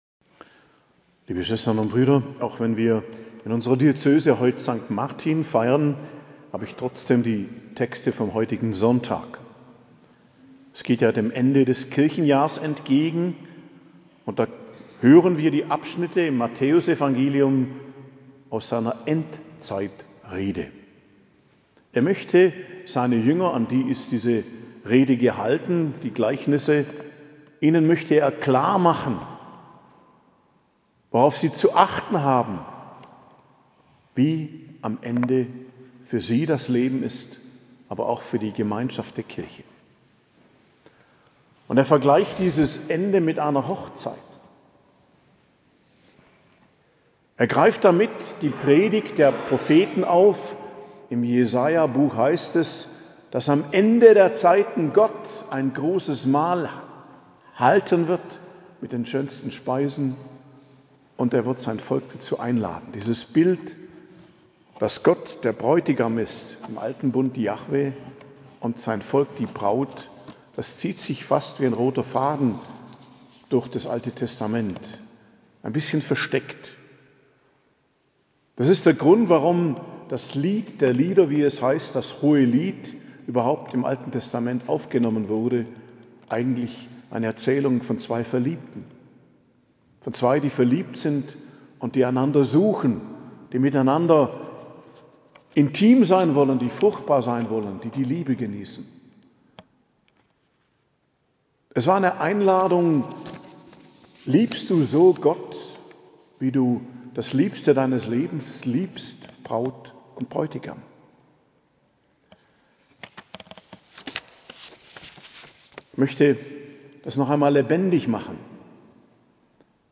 Predigt zum 32. Sonntag i.J., 12.11.2023 ~ Geistliches Zentrum Kloster Heiligkreuztal Podcast